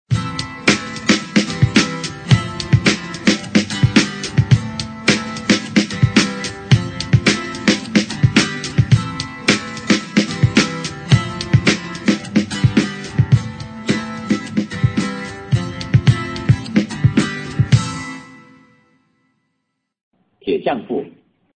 描述：电视或广播节目主题的精彩和有活力的介绍
Sample Rate 采样率16-Bit Stereo 16位立体声, 44.1 kHz